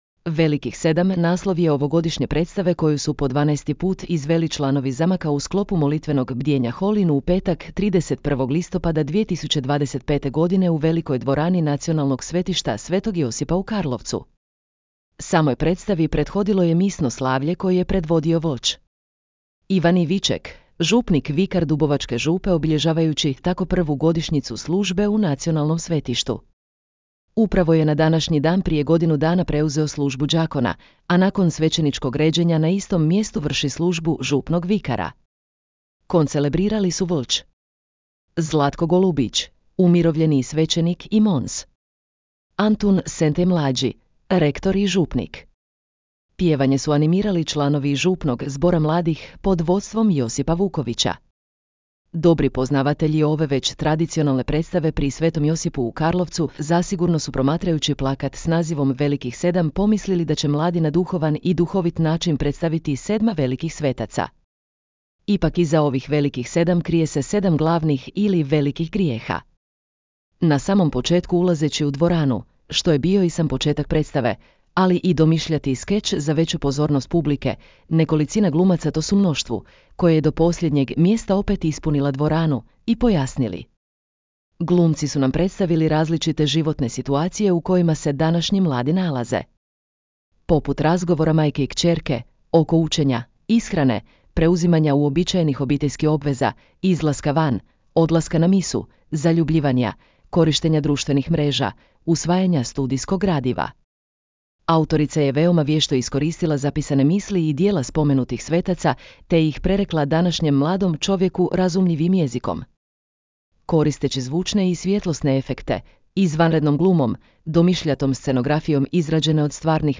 Predstava “Velikih 7” izvedena u sklopu molitvenog bdijenja Holywin
Veoma su vješto iskoristili zvučne efekte ali i plesne pokrete.